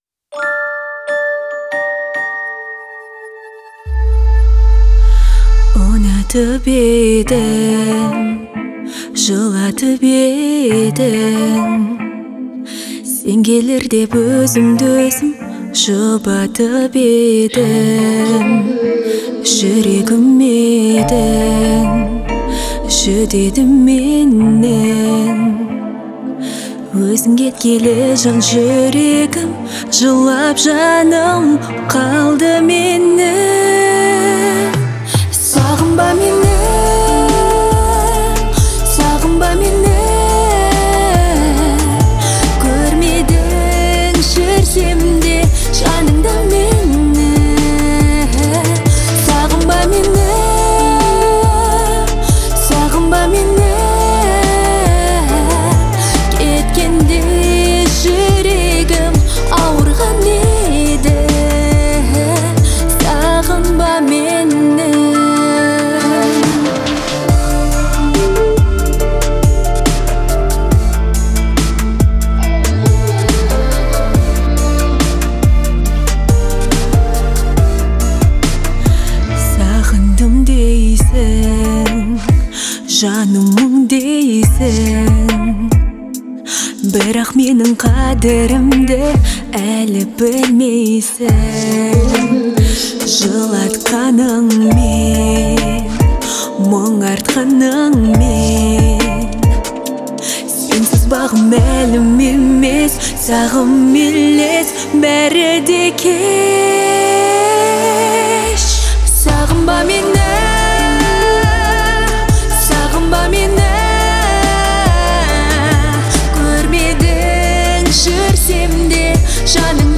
это трогательная и мелодичная песня в жанре поп